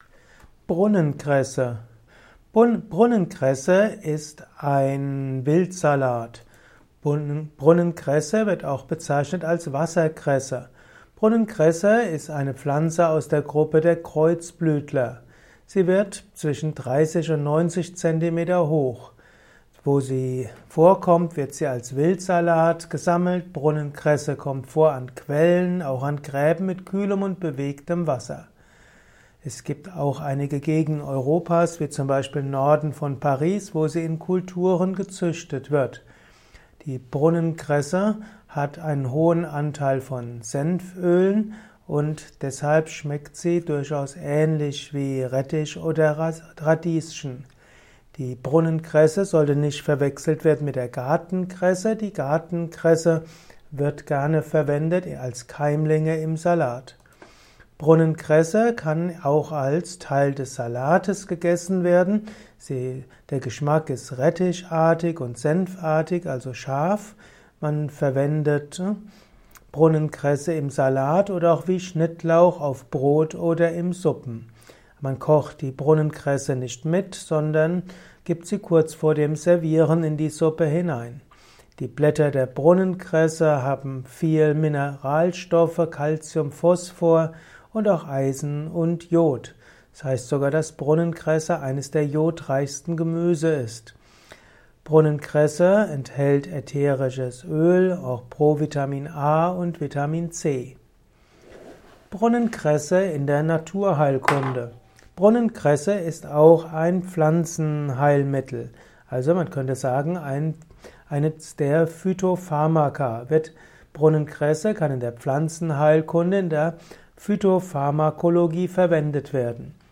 Erfahre mehr über Brunnenkresse in diesem Kurzvortrag